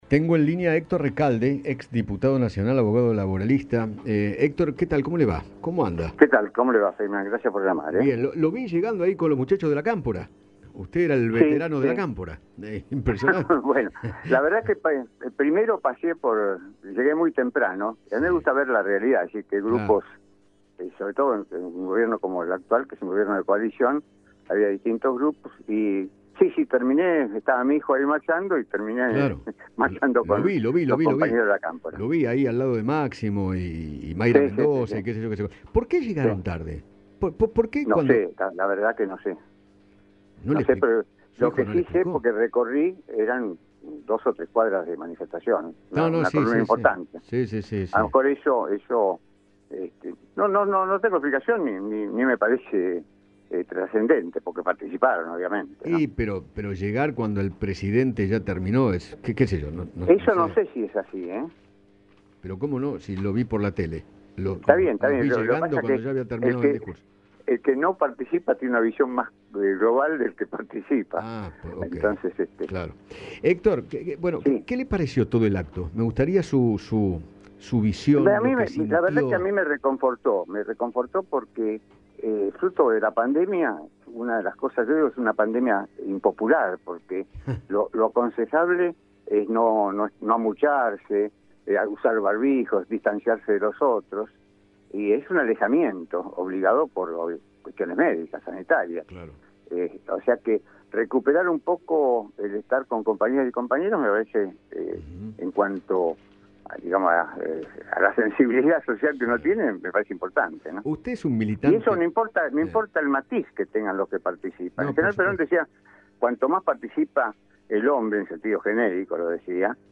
Héctor Recalde, ex diputado nacional, conversó con Eduardo Feinmann acerca del acto que encabezó ayer Alberto Fernández por el Día de la Militancia.